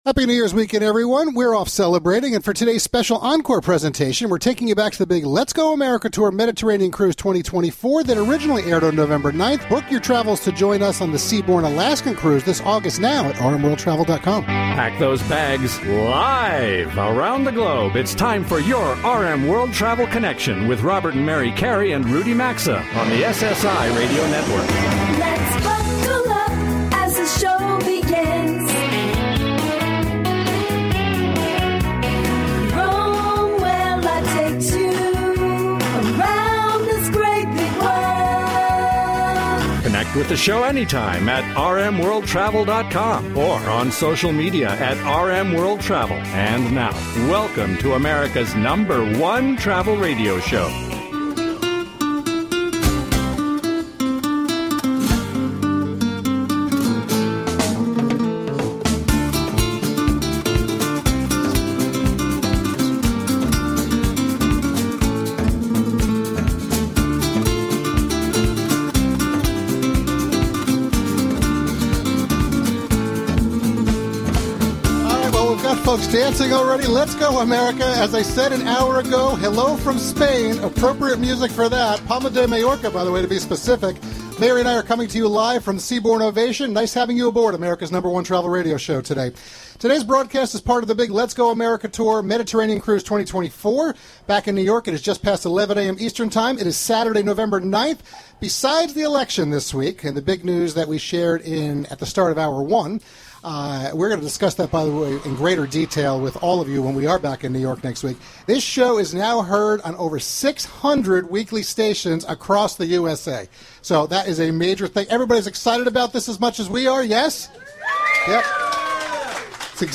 They provide straight-forward advice and commentary, inside scoop, tips/trends and more, as they connect with the audience and skillfully cover the world of travel, culture, and its allure through modern segments, on-location remote broadcasts, on-air showcasing and lively banter.